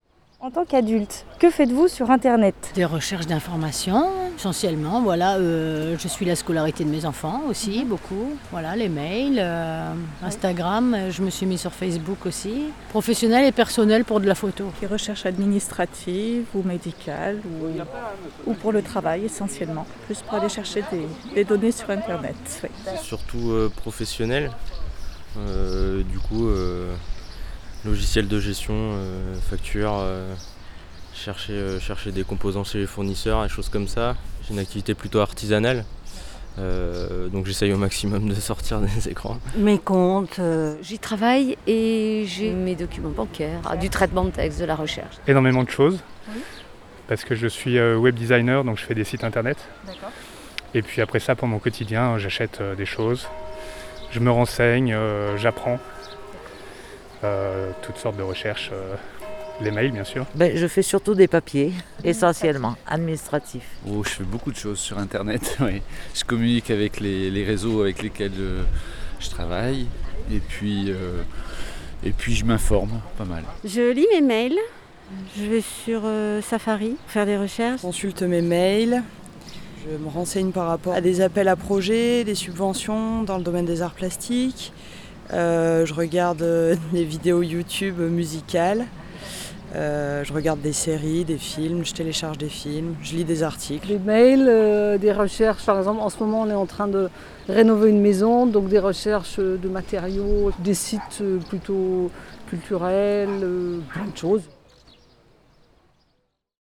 Un micro-trottoir réalisé sur le marché de Dieulefit auprès d’adultes et de parents, autour d’une question : Adultes, que faites-vous sur Internet ?
Micro-trottoir-3.mp3